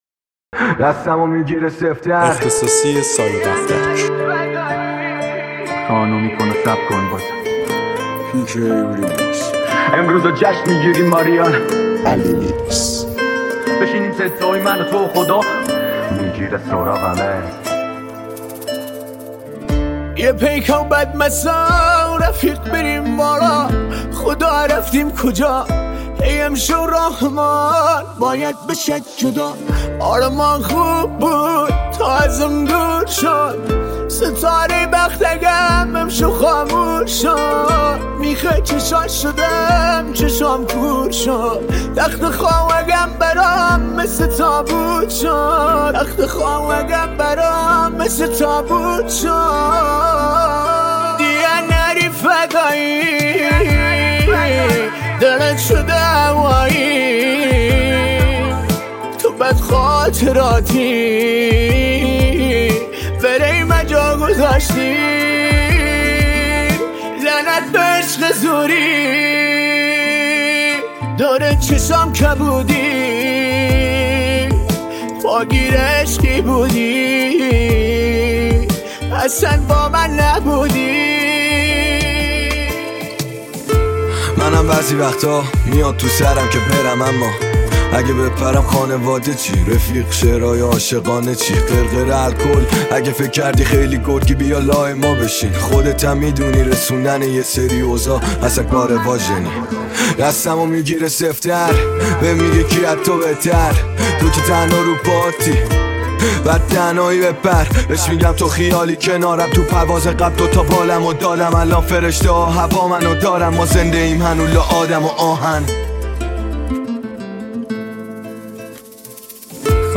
ریمیکس رپ تند اینستا